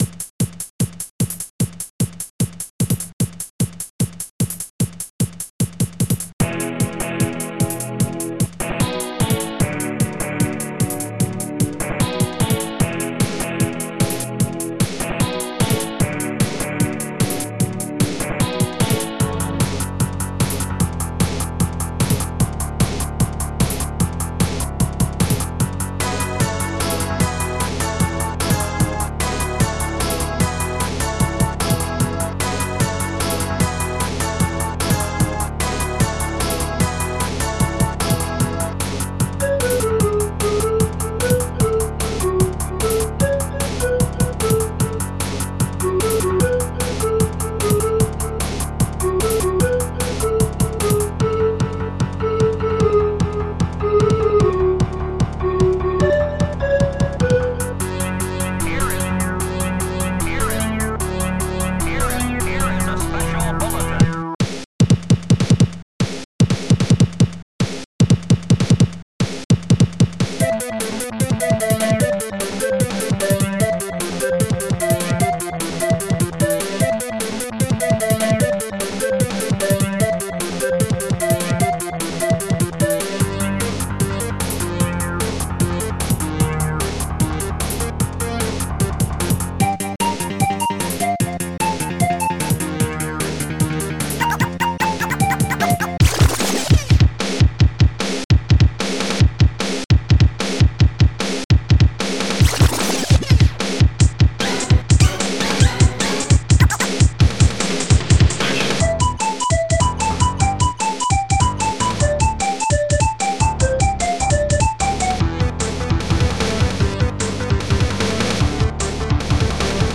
Protracker and family
st-07:BassGuitar
st-04:HighHat2
st-08:elecguitar2
st-01:HallBrass